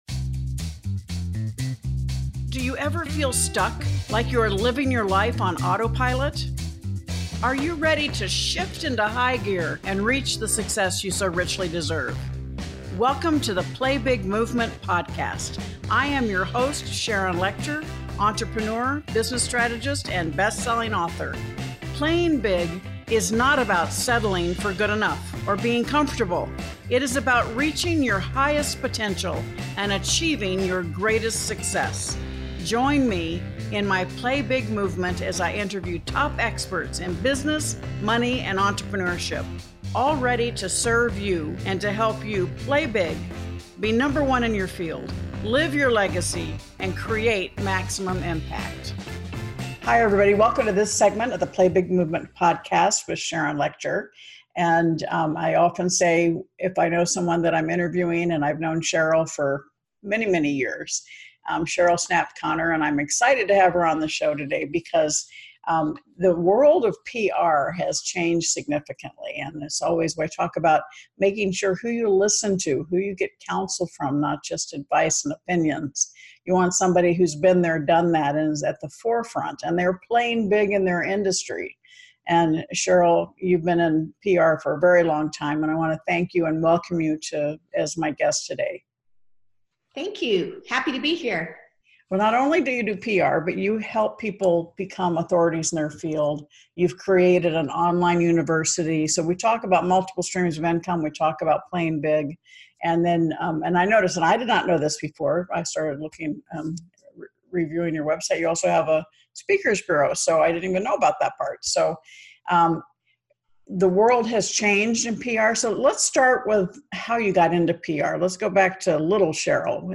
Today’s conversation is a opportunity to learn about the past, present, and future of PR from a true expert in the field.